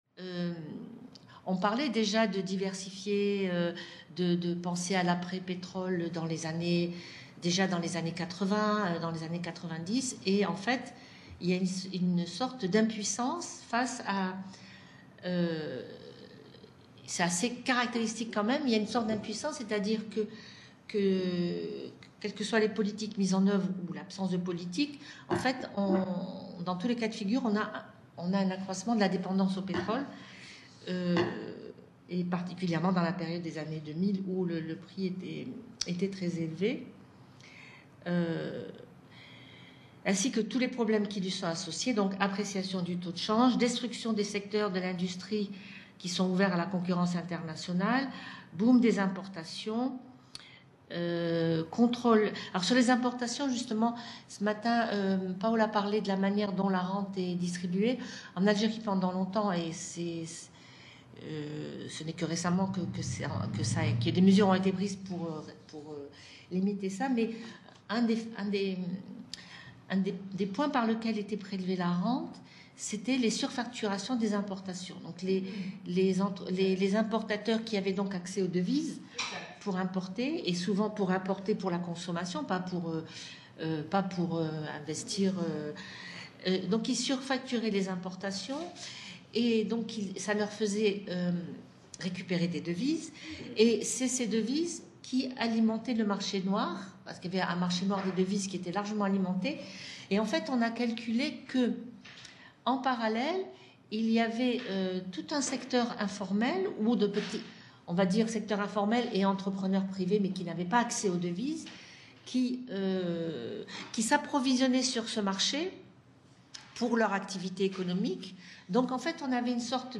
Journée d’études